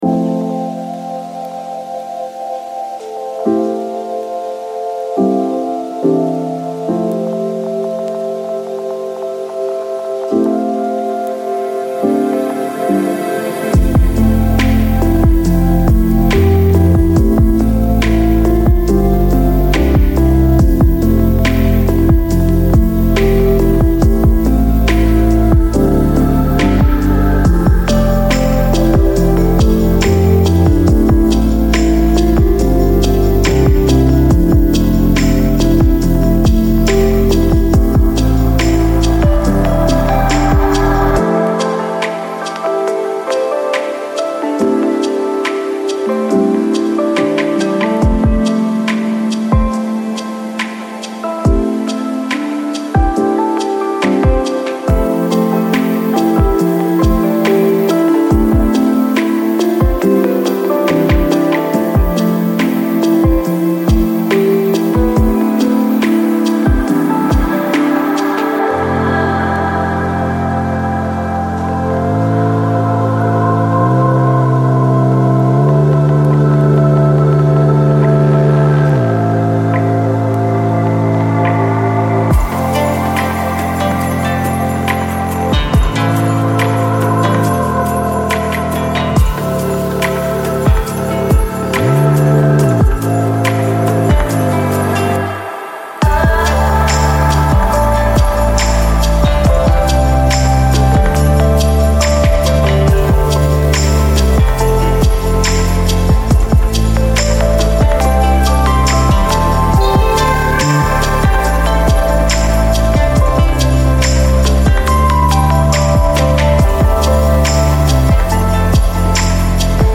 Ambiance Étude : Lecture Longue